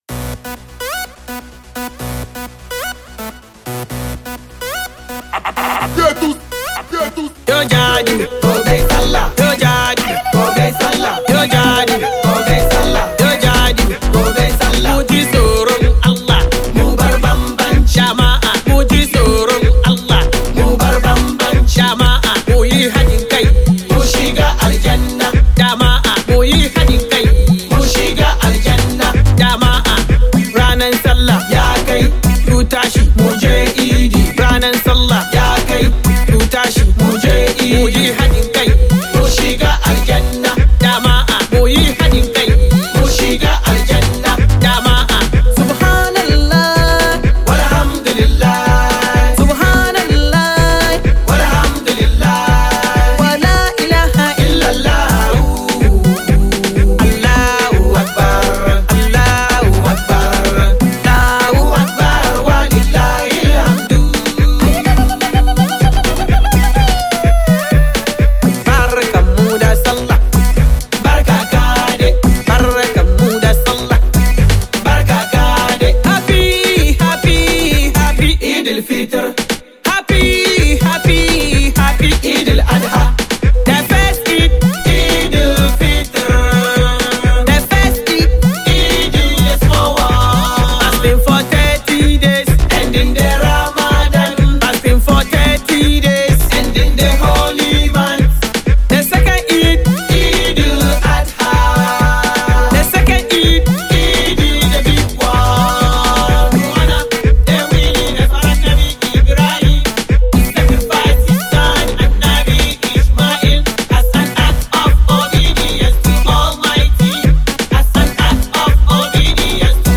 GospelMusic